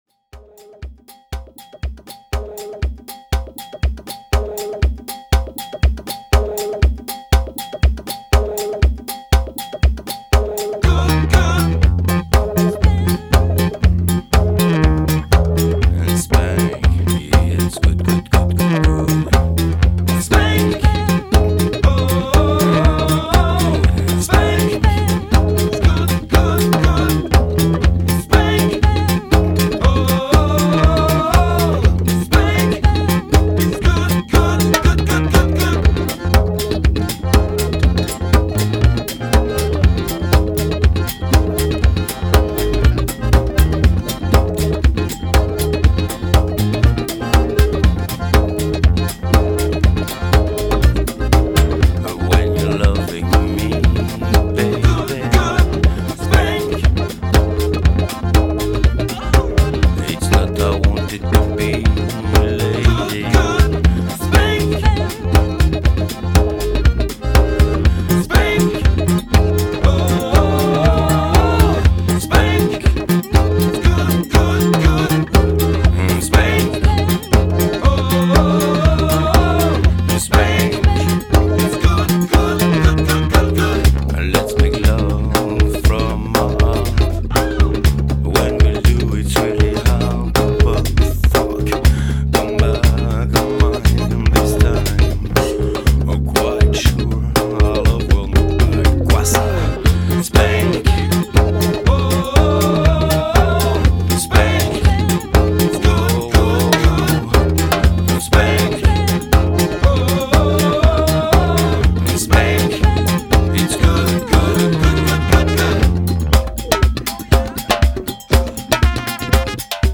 leftfield dance chops
an acoustic version of a disco classic